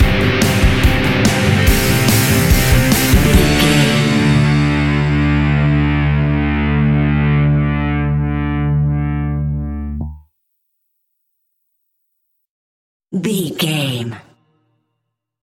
Epic / Action
Fast paced
Mixolydian
hard rock
blues rock
instrumentals
Rock Bass
heavy drums
distorted guitars
hammond organ